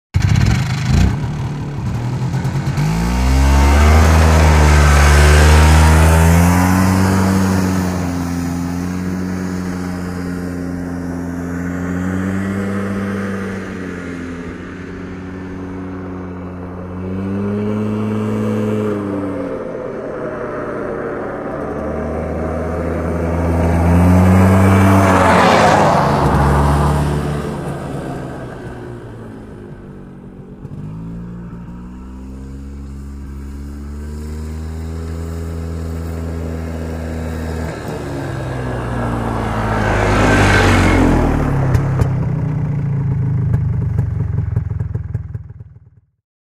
Квадроцикл делает маневры, ездит мимо
• Категория: Мотоциклы и мопеды
• Качество: Высокое